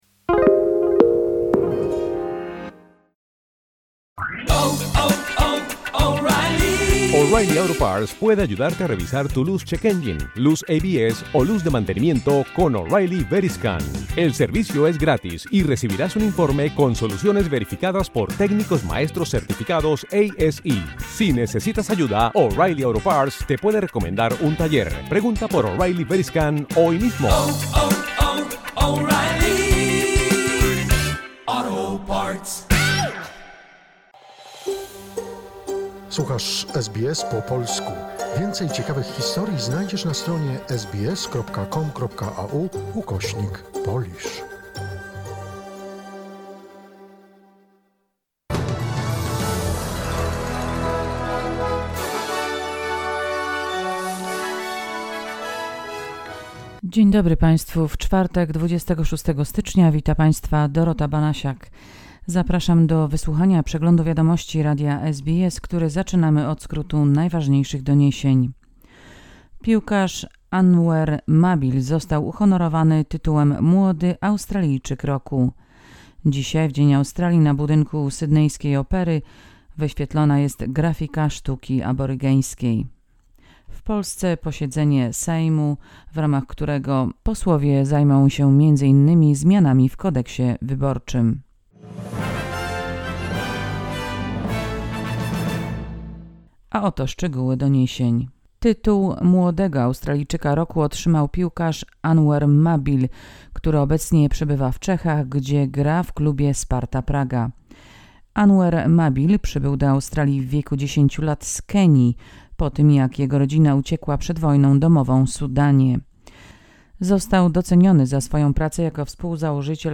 Wiadomości 26 stycznia 2023 - SBS News Flash